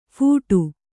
♪ phūṭu